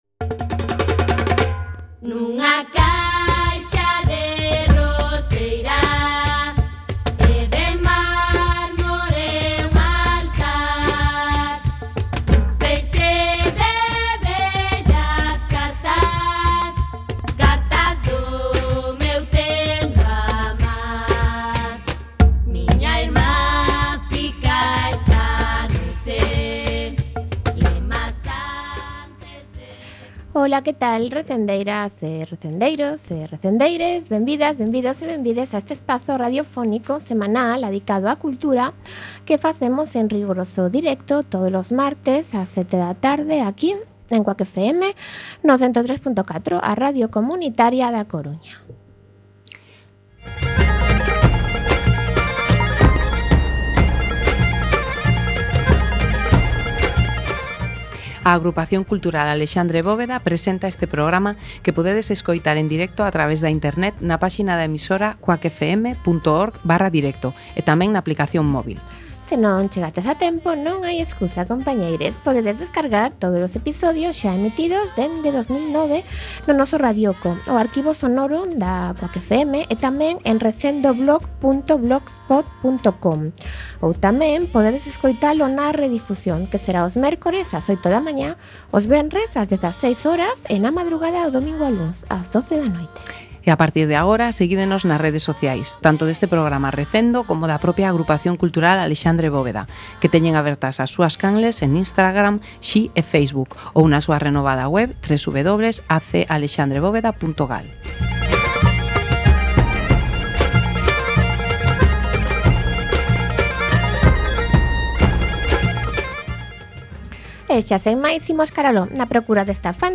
Programa número 511, no que entrevistamos ás coordinadoras e varias integrantes do Club de Lectura Queeruña.